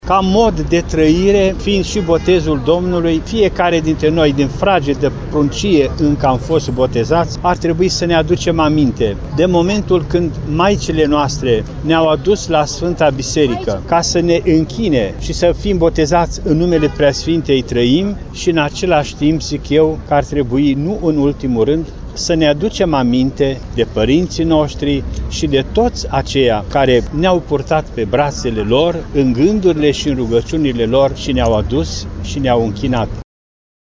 Mii de oameni au participat, astăzi, la Catedrala Mitropolitană din Timişoara, la slujba de Bobotează.
Liturghia de Bobotează a fost oficiată Înalt Preasfințitul Ioan Selejan. Cu prilejul Botezului Domnului, Înalt Prea Sfinţia Sa Ioan, Mitropolitul Banatului, a lansat un nou îndemn la credinţă: